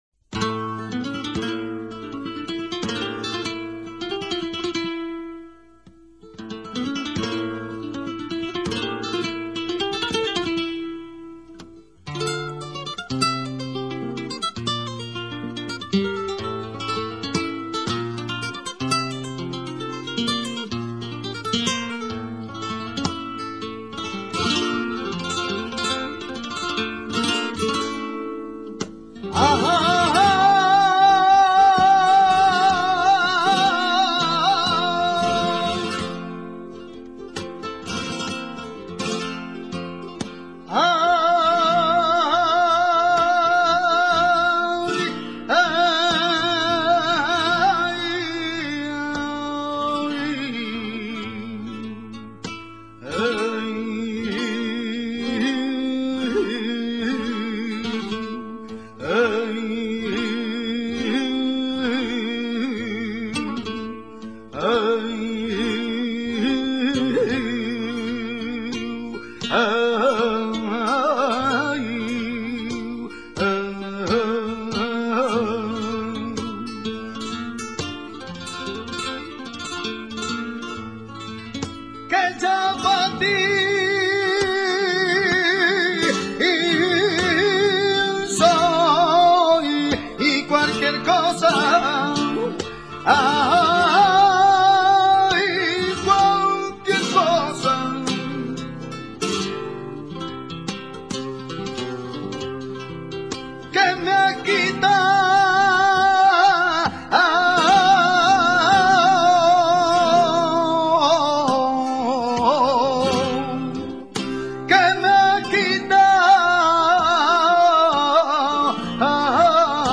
Sonidos y Palos del Flamenco
cana.mp3